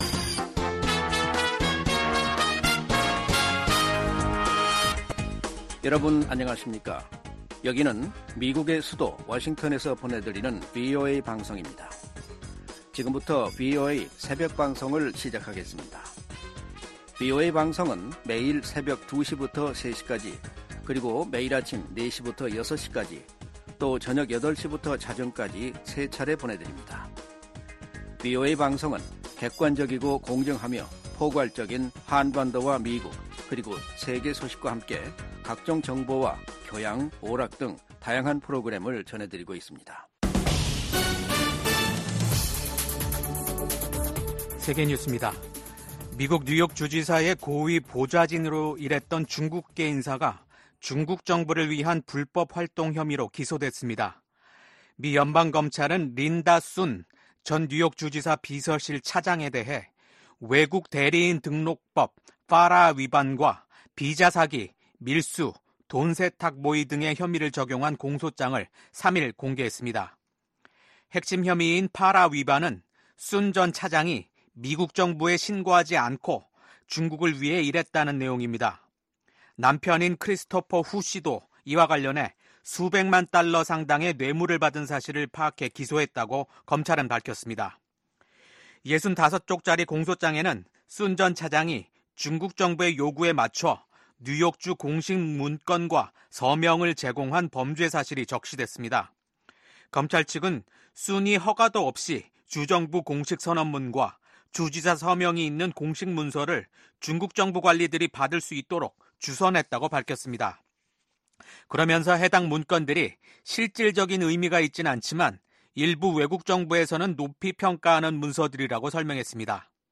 VOA 한국어 '출발 뉴스 쇼', 2024년 9월 5일 방송입니다. 미국 국무부가 한국 정부의 대북 라디오 방송 지원 방침을 지지한다는 입장을 밝혔습니다. 기시다 후미오 일본 총리가 퇴임을 앞두고 한국을 방문해 윤석열 대통령과 회담을 합니다. 북한 열병식 훈련장 인근에 버스로 추정되는 차량 수십 대가 집결했습니다.